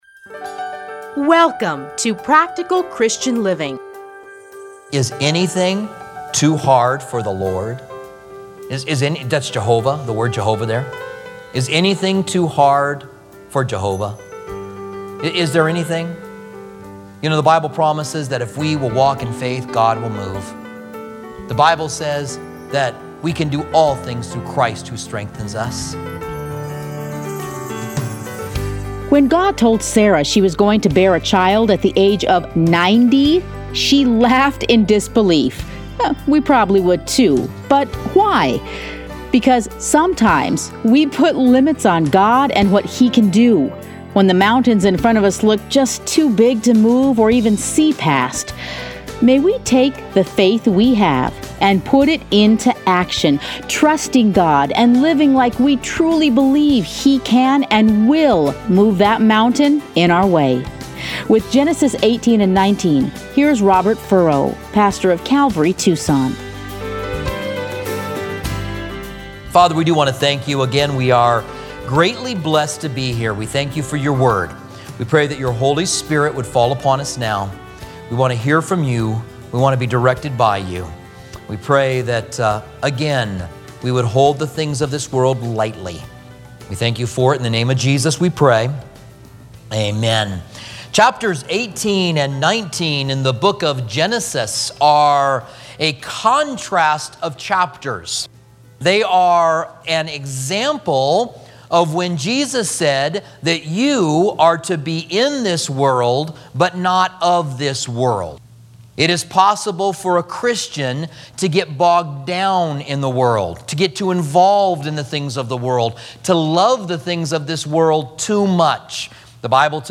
Listen here to a teaching from Genesis.